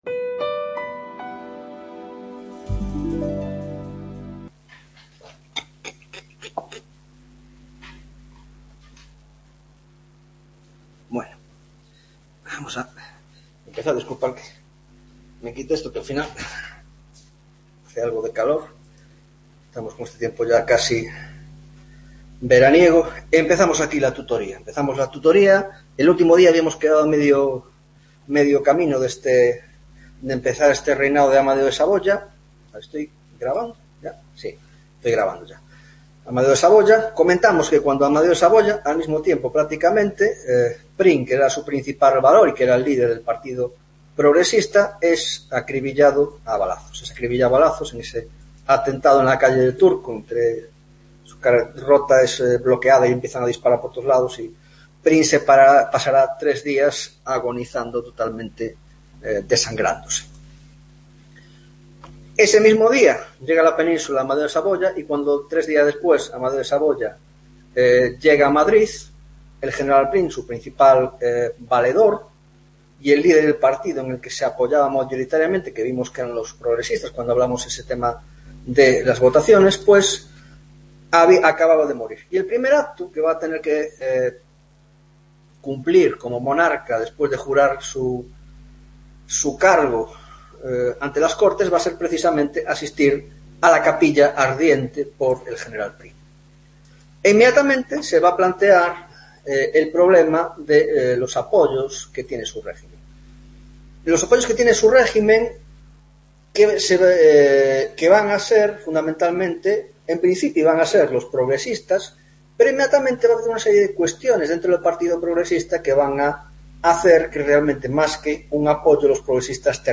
21ª tutoria de Historia Contemporánea - Sexenio Democrático (2ª parte)